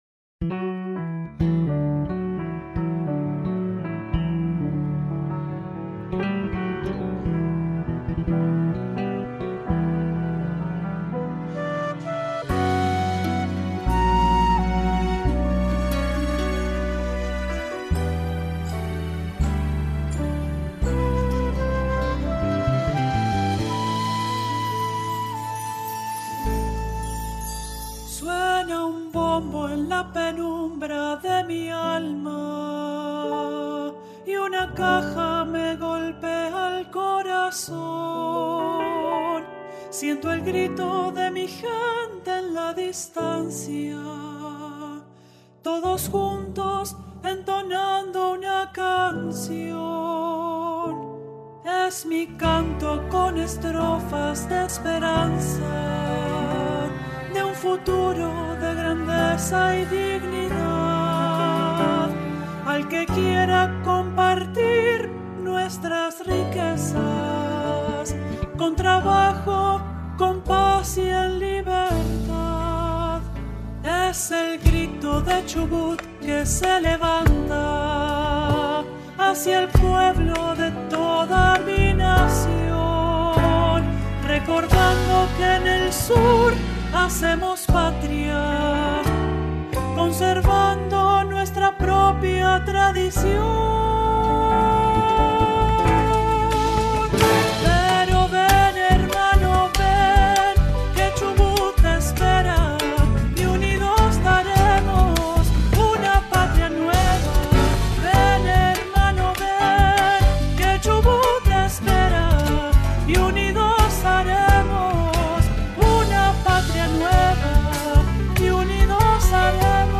Audio del Himno de Chubut - Versión reducida
himno-provincia-chubut-te-espera.mp3